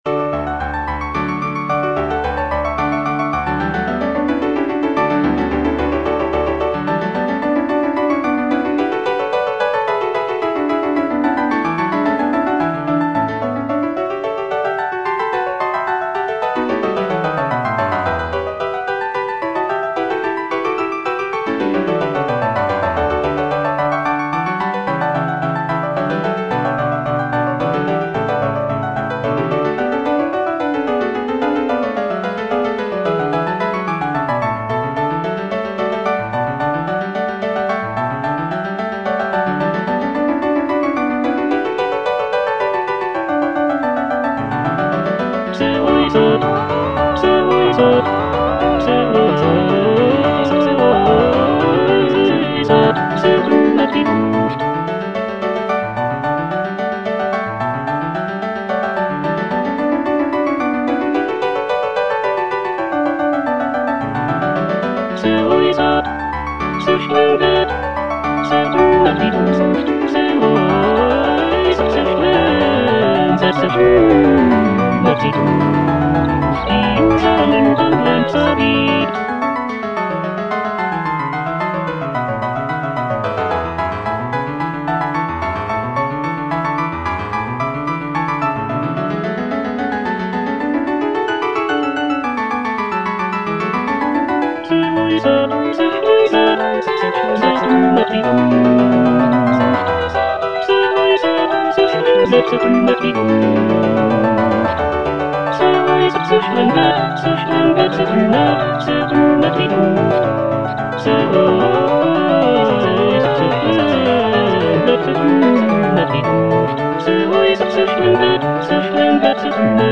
Choralplayer playing Cantata
The music is lively and celebratory, with intricate counterpoint and virtuosic vocal lines.